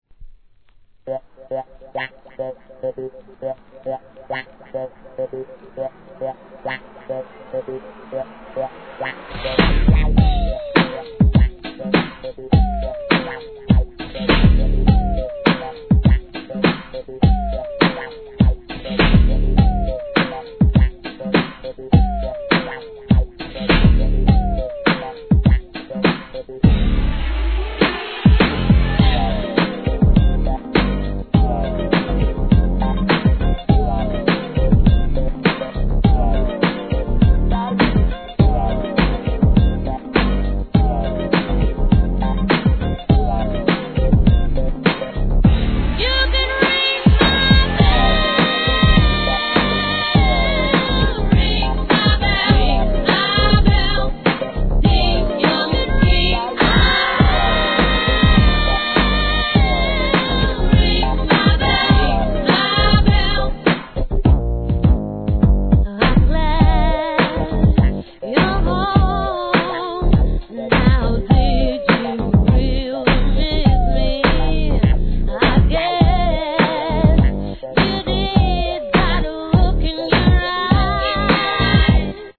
HIP HOP/R&B
当時の流行に合わせたミドルテンポのキャッチーな好リメイク!!